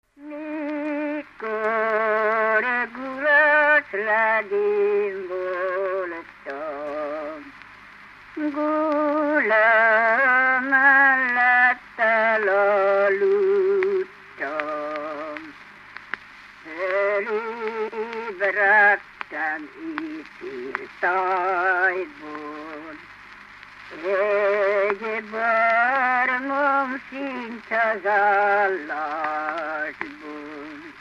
Dunántúl - Tolna vm. - Ozora
ének
Stílus: 6. Duda-kanász mulattató stílus
Szótagszám: 8.8.8.8
Kadencia: 1 (1) 1 1